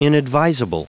Prononciation du mot inadvisable en anglais (fichier audio)
Prononciation du mot : inadvisable